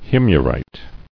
[Him·yar·ite]